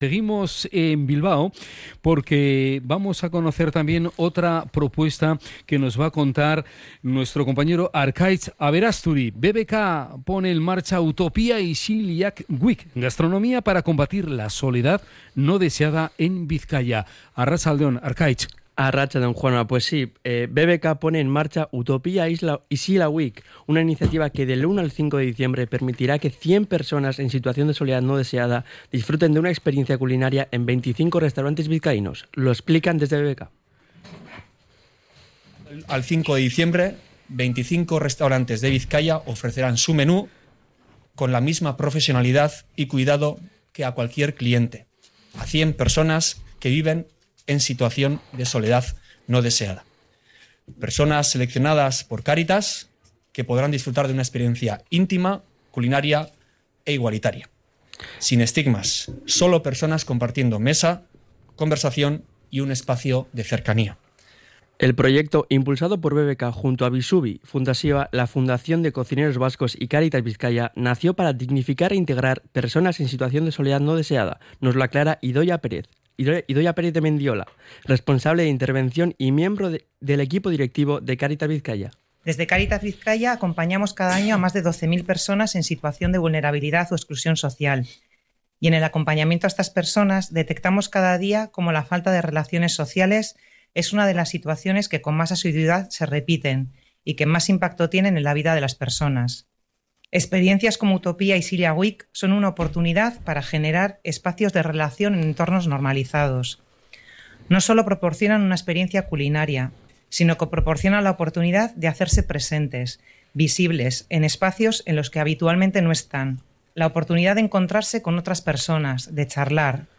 Presentación de Utopia Isila week en el Restaurante Bikandi Etxea / RADIO POPULAR - HERRI IRRATIA
CRONICA-ISILIK.mp3